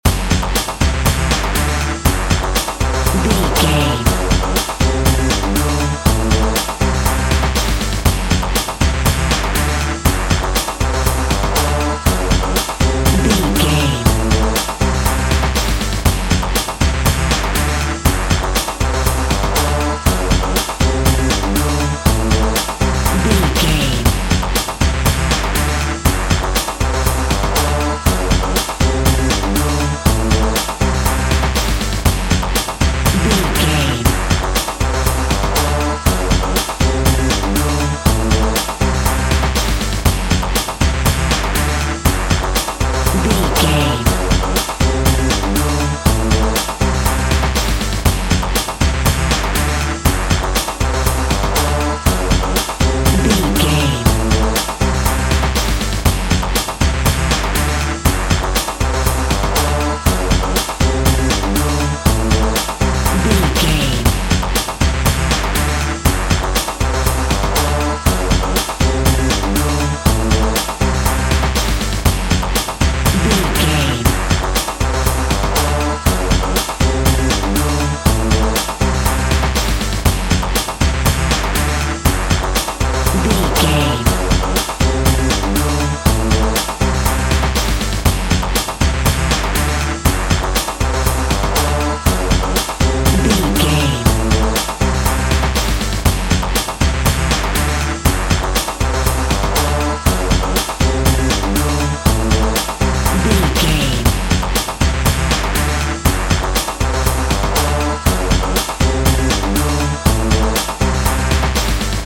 Epic / Action
Fast paced
Locrian
F#
Fast
aggressive
powerful
dark
driving
energetic
intense
drum machine
synthesiser
breakbeat
power rock
synth drums
synth leads
synth bass